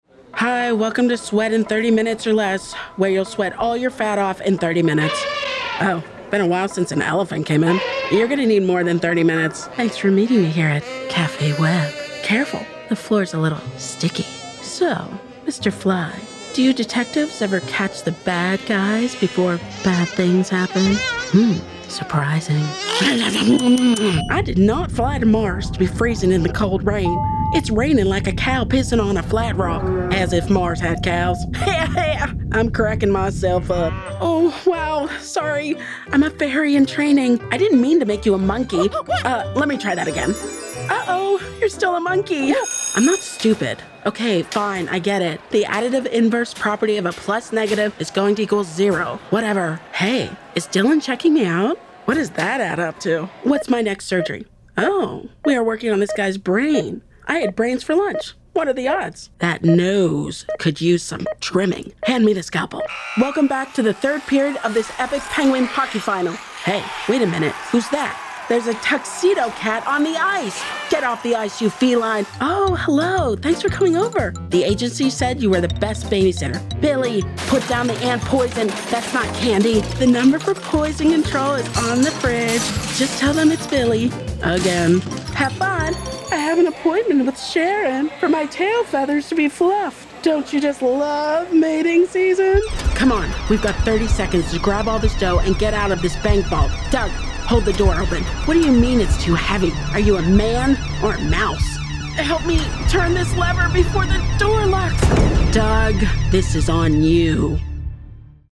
A 30s voice over actor, mom and hockey in
Animation Demo Reel
British, New York, Boston, Southern Midwest Irish
Character Voice